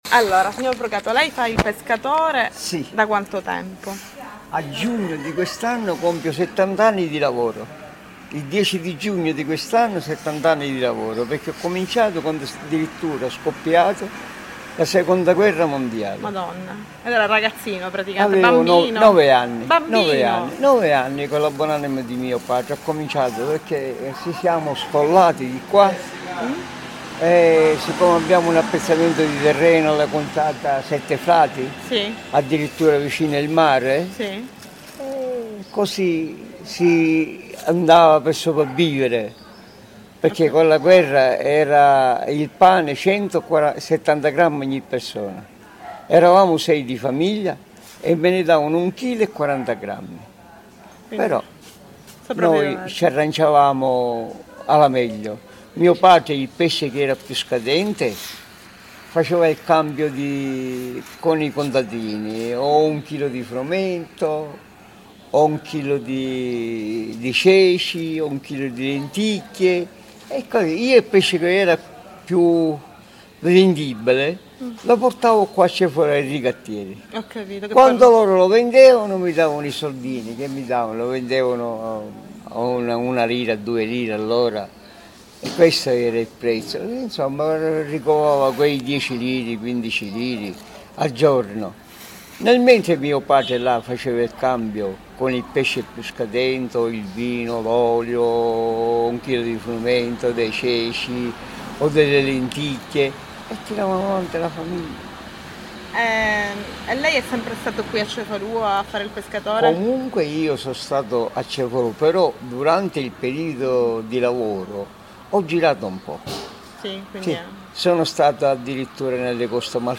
archivi.cricd_.it-Pesca-e-pescatori-a-Cefalu-–-audio-–-cricd.mp3